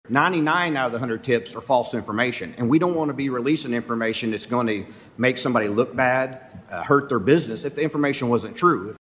CLICK HERE to listen to commentary from House Member John George.